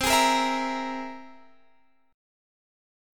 Listen to C+7 strummed